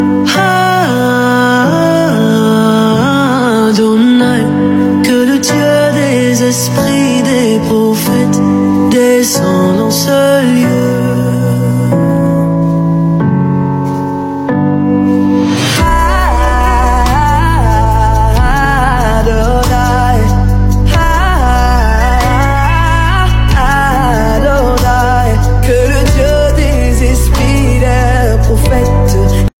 Through powerful lyrics and soul-stirring production